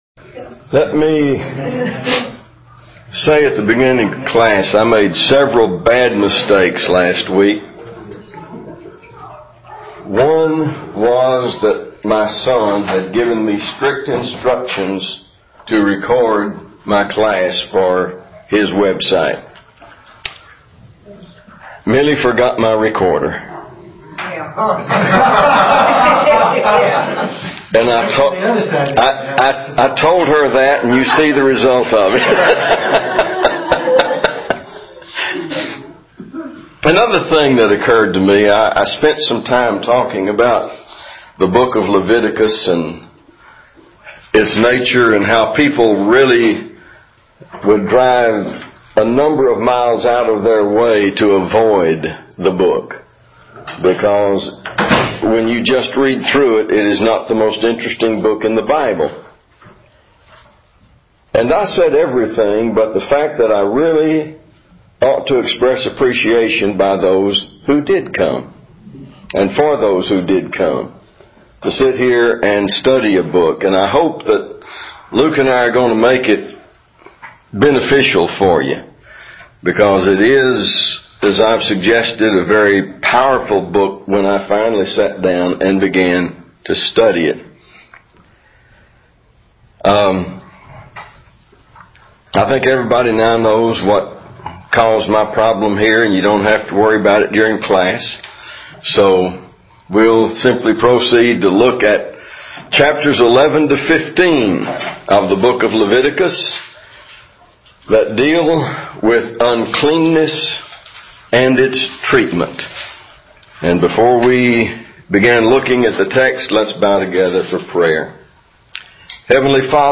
Leviticus-Lesson-4-Audio-File.mp3